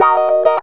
GTR 2 A#M110.wav